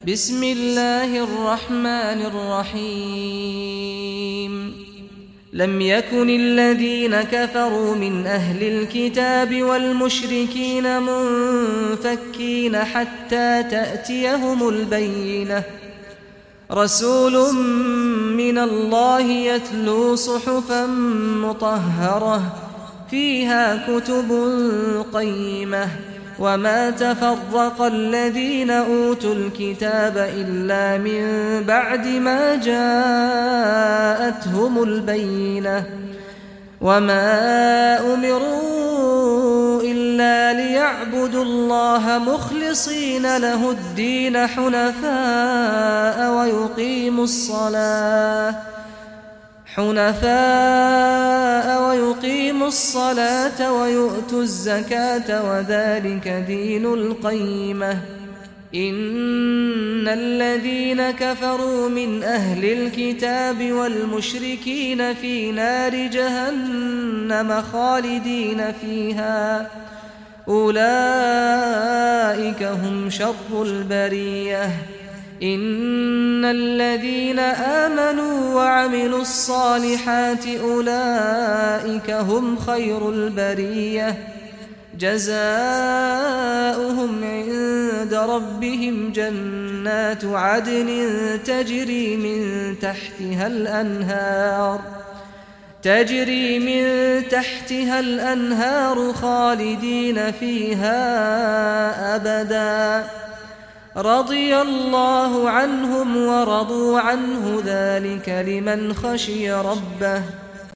Surah Al Bayyinah Recitation by Sheikh Saad Ghamdi
Surah Al Bayyinah, listen or play online mp3 tilawat / recitation in Arabic in the voice of Sheikh Saad al Ghamdi.